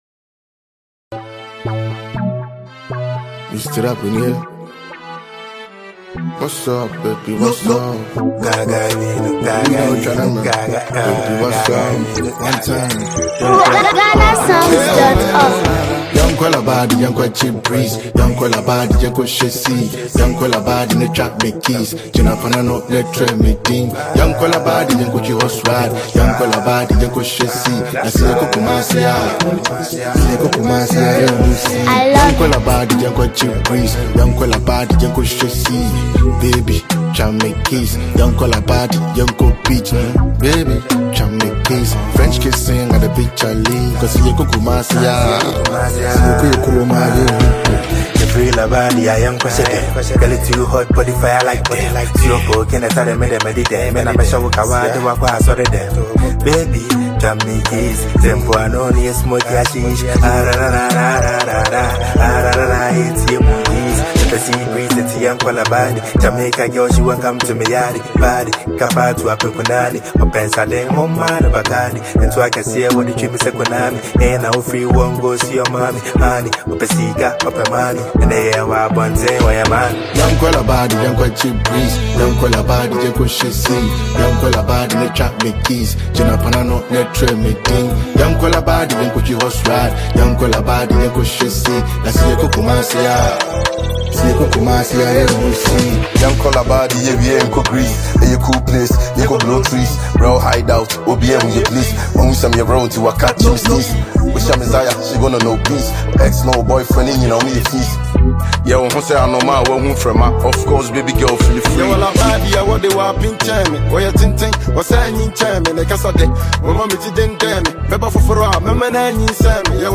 With its catchy hooks and confident delivery